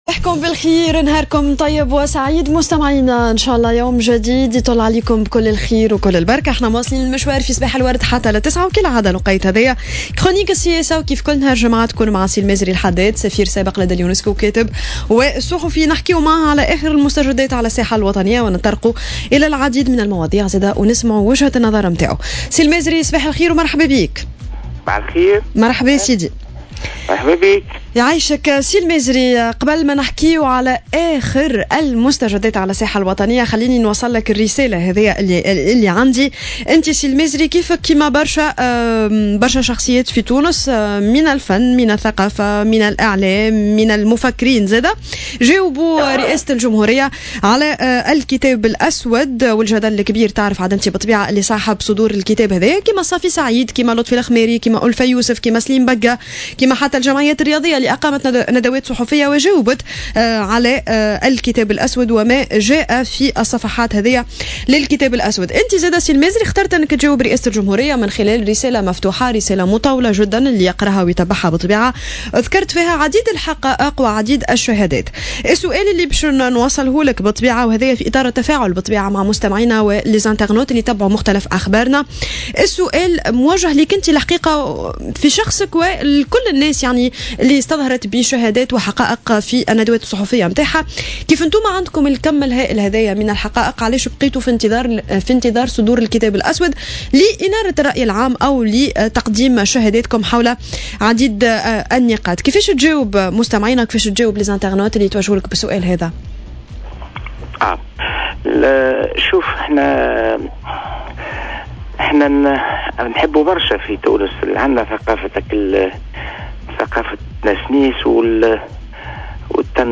قال الكاتب و الصحفي والسفير السابق لدى اليونسكو،المازري الحداد في مداخلة له اليوم في برنامج "صباح الورد" على "جوهرة أف أم" إنه ليس من المعقول ترشيح مصطفى الفيلالي لرئاسة الحكومة.